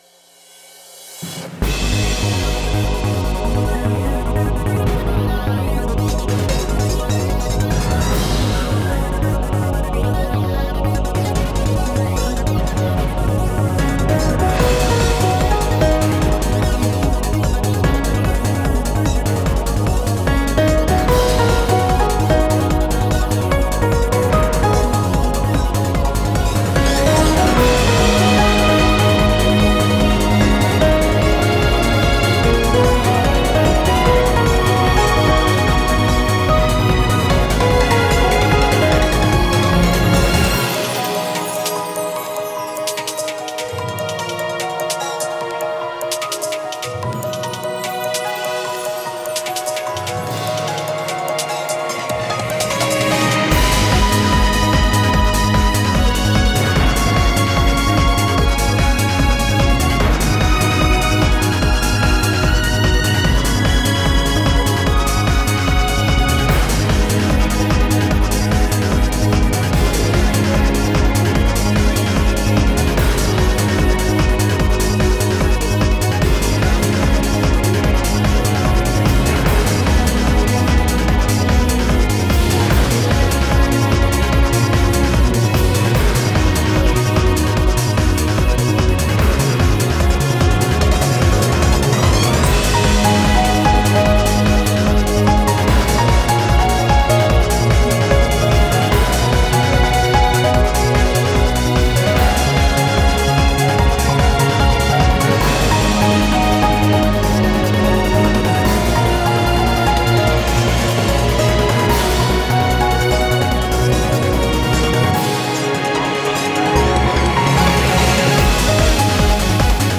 Cinematic, Epic, Uplifting, emotional, Feelings of Justice.